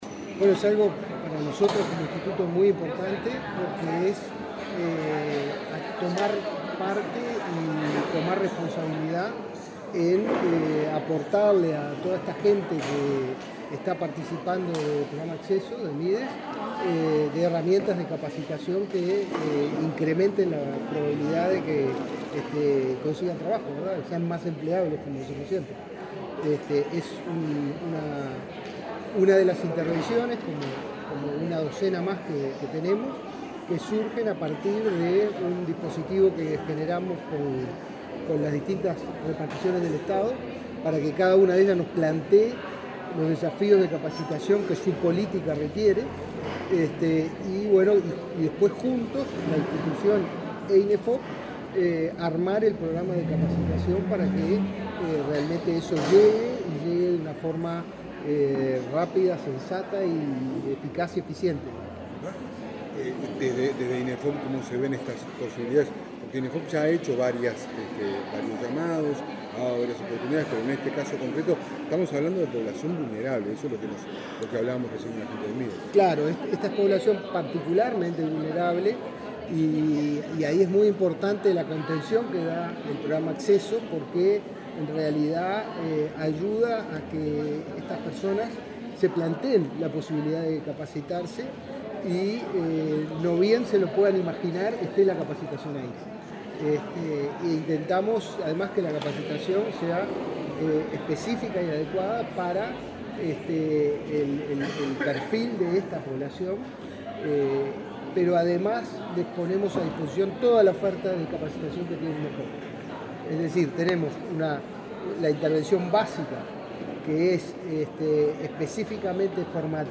Declaraciones del director de Inefop